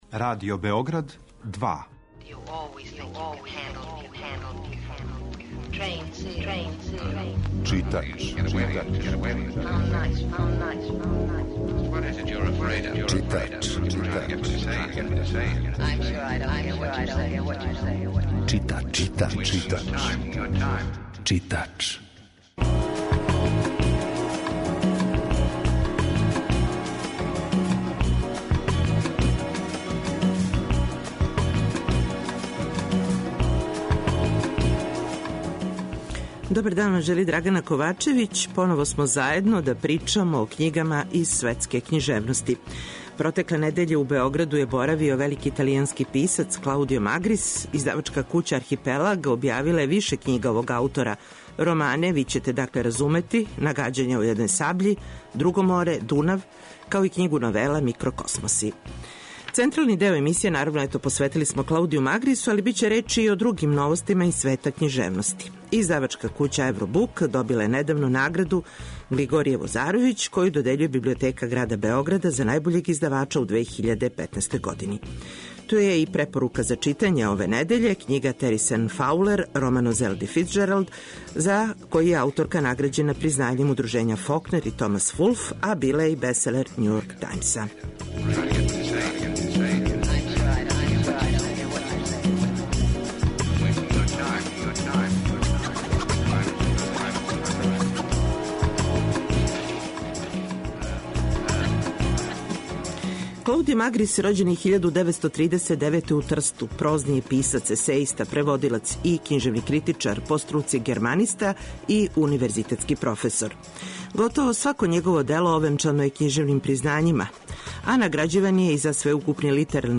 У данашњем издању емисије Читач , чућете разговор који је вођен током боравка Магриса у Београду.